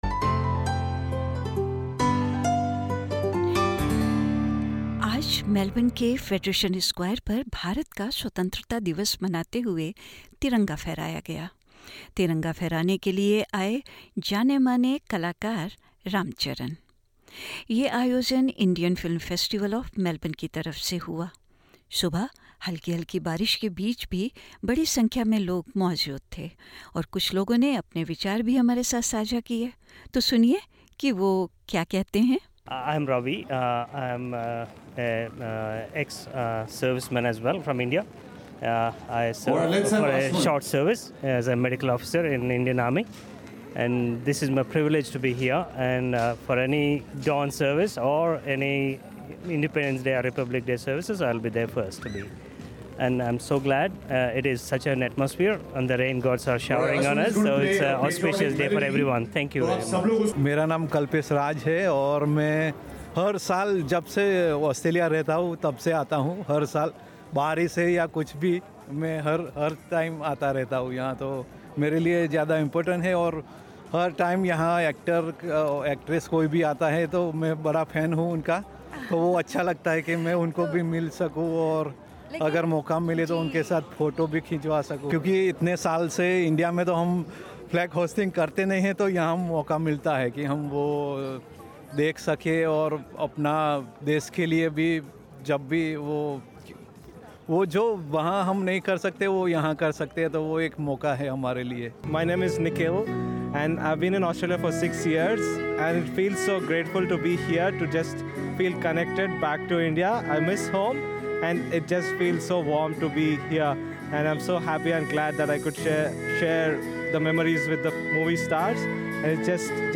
मेलबर्न के फेडरेशन स्क्वायर पर शनिवार 17 अगस्त को भारत का 78वां स्वतंत्रता दिवस रंगारंग कार्यक्रम के साथ मनाया गया। यह कार्यक्रम इंडियन फिल्म फेस्टीवल ऑफ मेलबर्न के एक हिस्से के रूप में था।
इस पॉडकास्ट में सुनिये कुछ प्रवासी भारतीयों के विचार ।